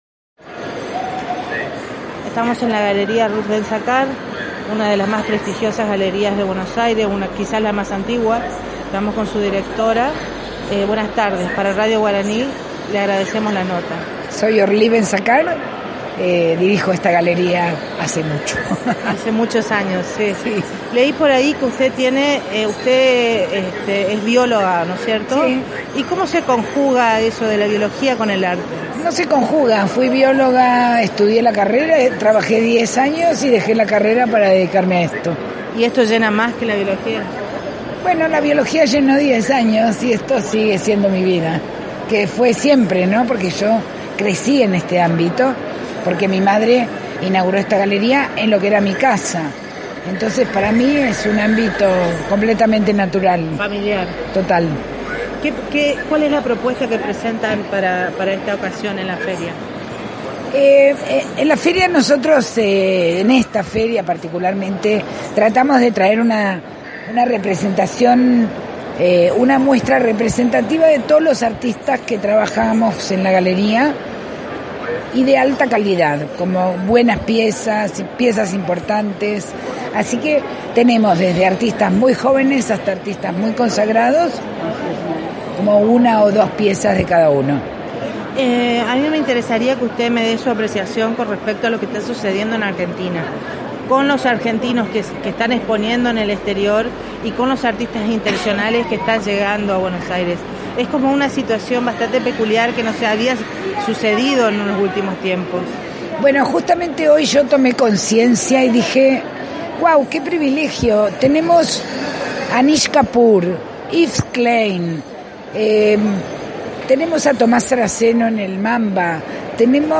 (Audio) En el marco de la Feria ArteBA